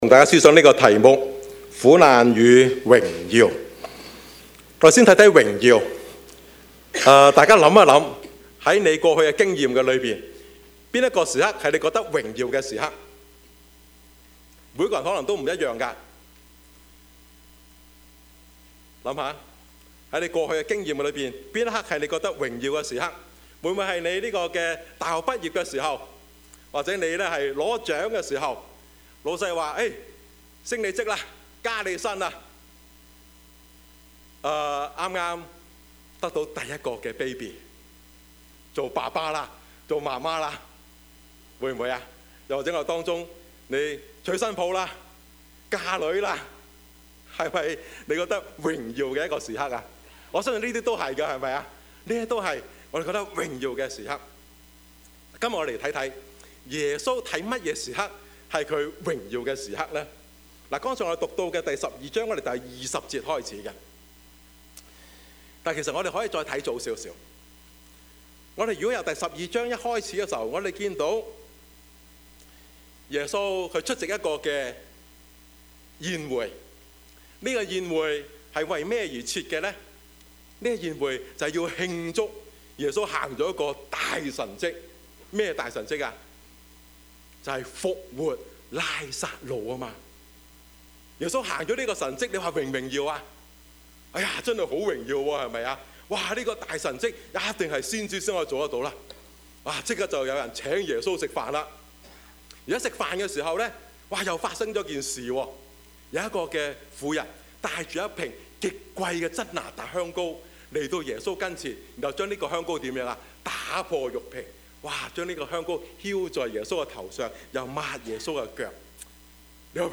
Service Type: 主日崇拜
Topics: 主日證道 « 做好人可以上天堂嗎?